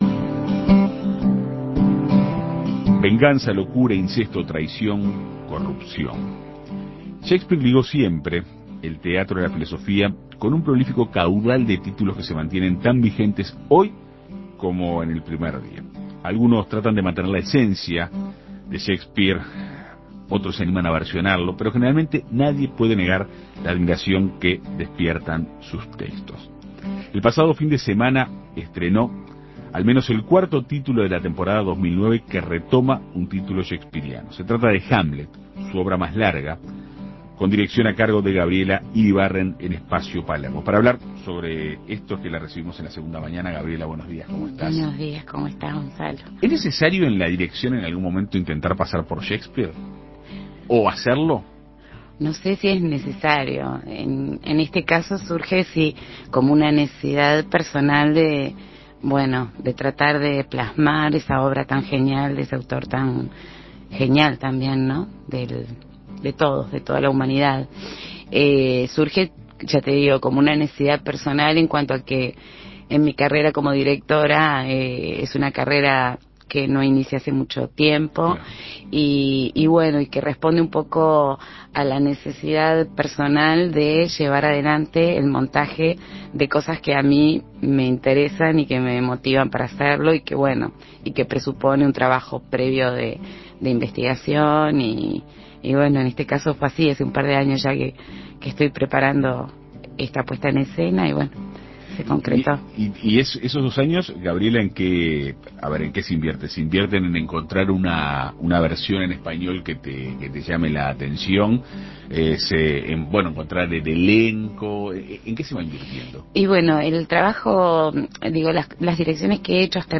En Perspectiva Segunda Mañana dialogó con ella para conocer los detalles de la obra.